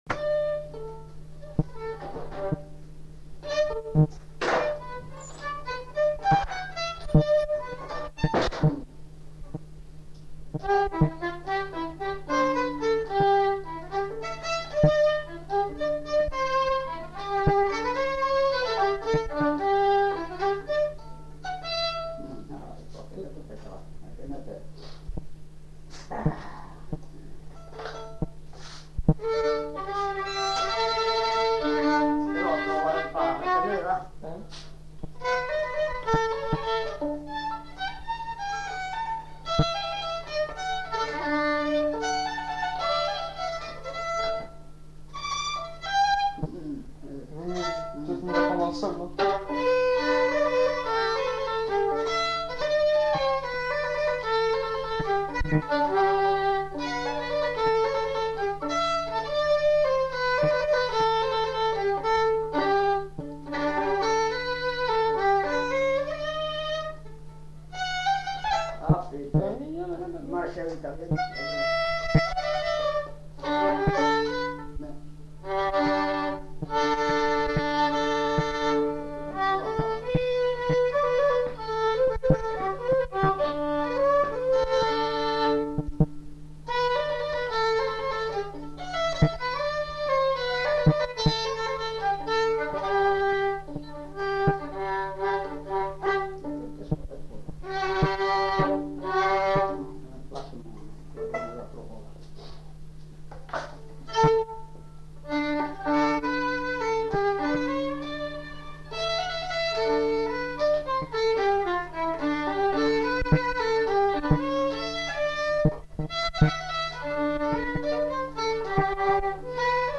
Valse
Lieu : Saint-Michel-de-Castelnau
Genre : morceau instrumental
Instrument de musique : violon
Danse : valse
Notes consultables : Beaucoup d'hésitations avant de retrouver l'air.